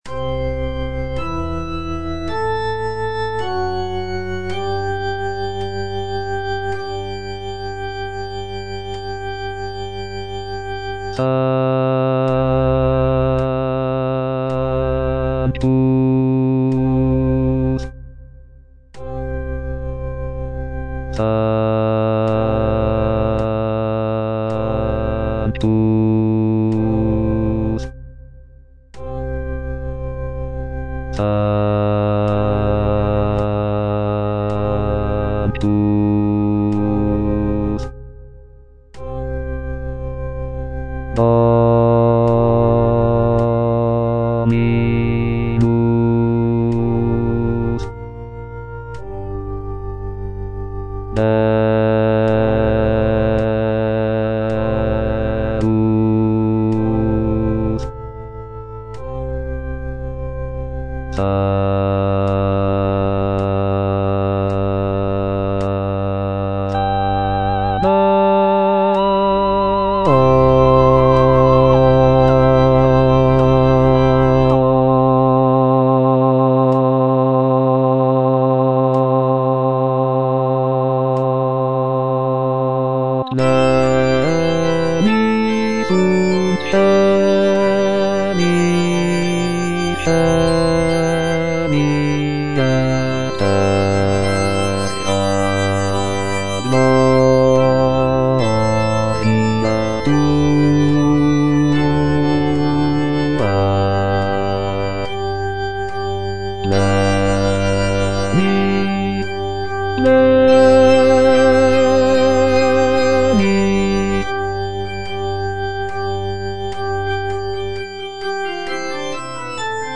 (Voice with metronome) Ads stop